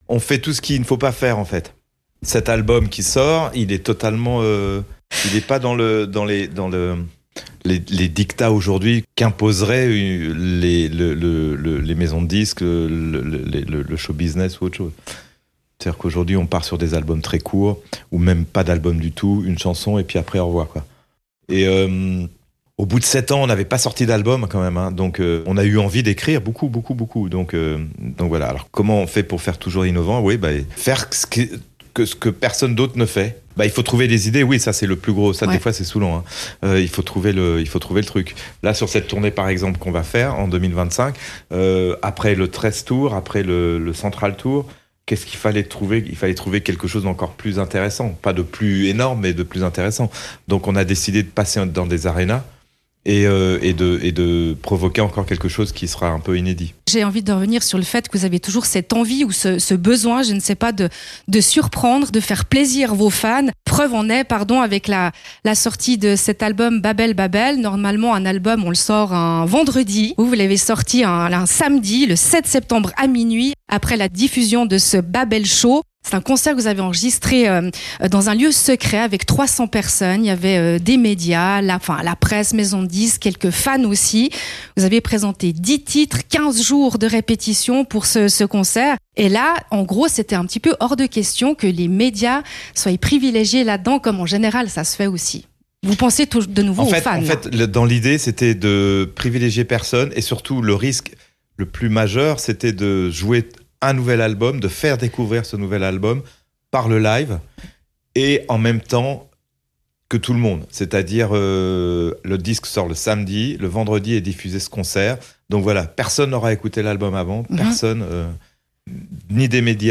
Interview: Radio FR